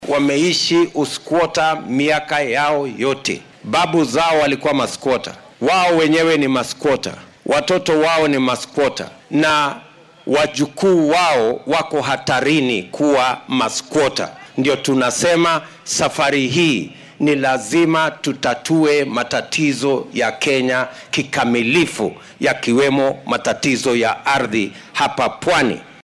Ku xigeenka madaxweynaha dalka William Ruto oo isku soo bax siyaasadeed maanta ku qabtay Mombasa ayaa hoosta ka xarriiqay inay qasab tahay in la xalliyo dhibaatooyinka dhanka dhulalka ee sanado badan qaar ka mid ah shacabka gobolka xeebta ka dhigay kuwo aan lahayn meel rasmi oo ay deggan yihiin.